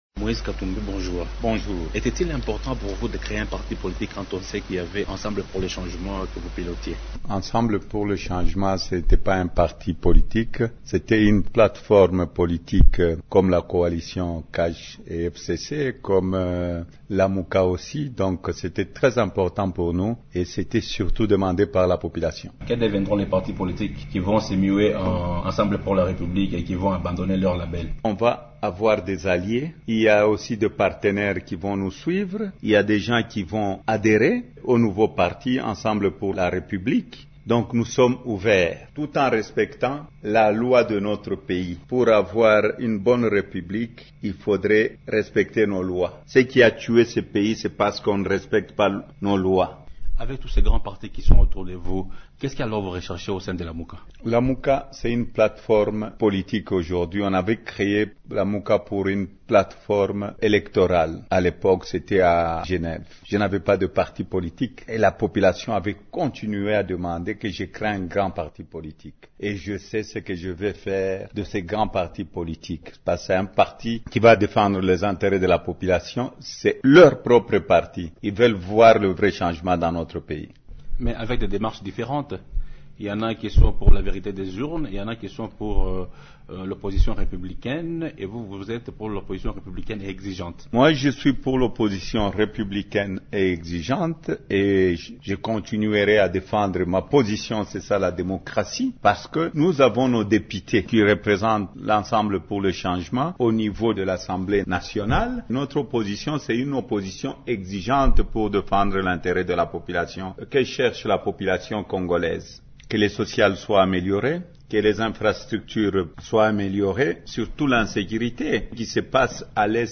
Vous pouvez suivre dans cet extrait sonore, Moise Katubmi. Il revient sur ce qui les motivations qui l’ont poussé à créer son propre parti politique.